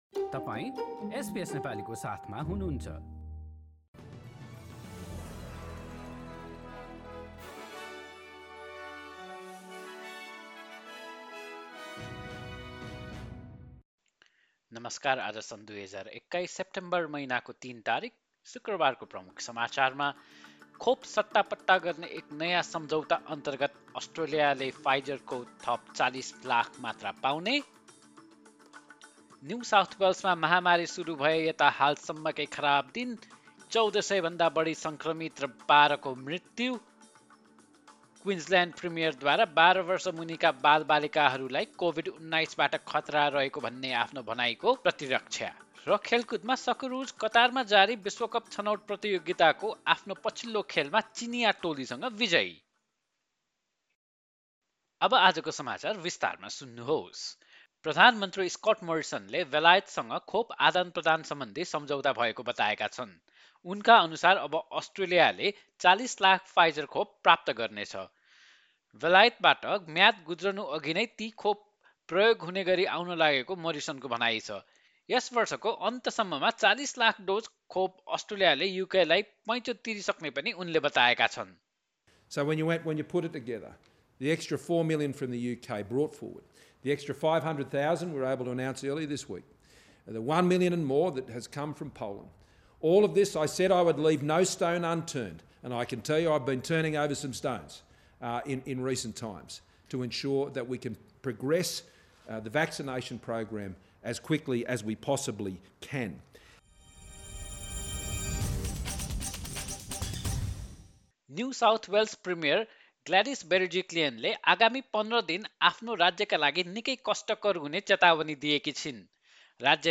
एसबीएस नेपाली अस्ट्रेलिया समाचार: शुक्रवार ३ सेप्टेम्बर २०२१